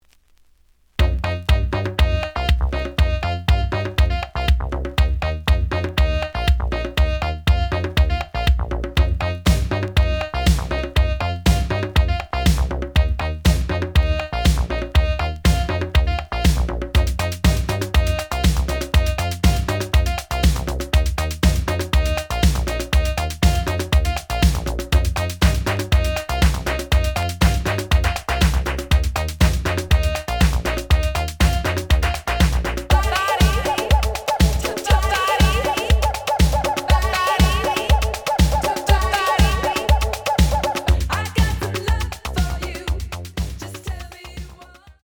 The audio sample is recorded from the actual item.
●Genre: House / Techno